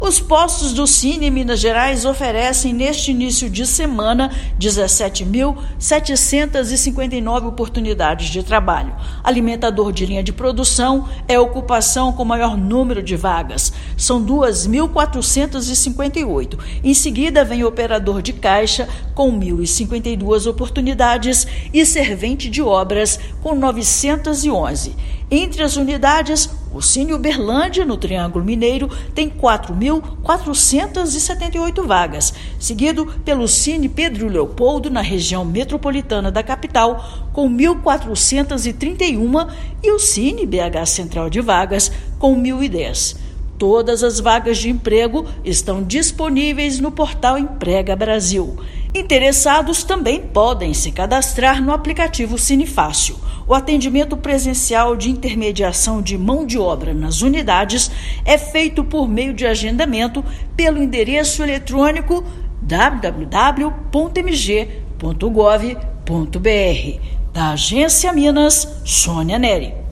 Alimentador de linha de produção é a ocupação com maior número de oportunidades. Ouça matéria de rádio.